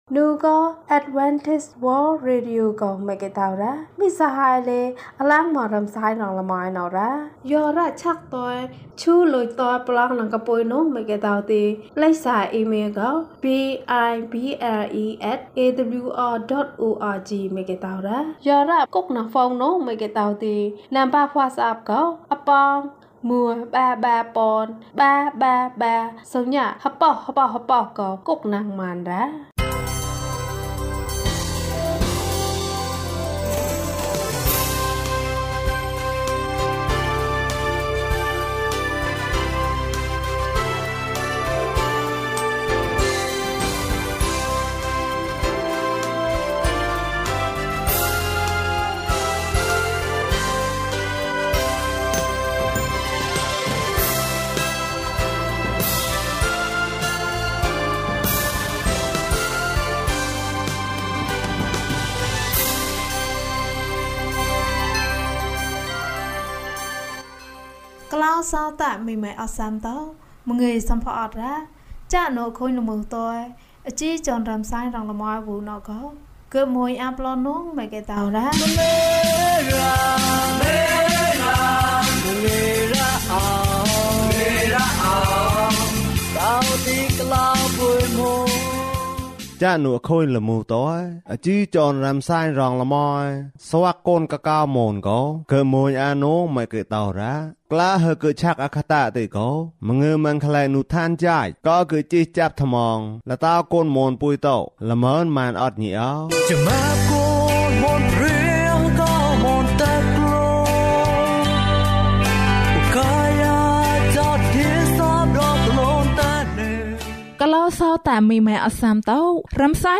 ခရစ်တော်ထံသို့ ခြေလှမ်း။၄၈ ကျန်းမာခြင်းအကြောင်းအရာ။ ဓမ္မသီချင်း။ တရားဒေသနာ။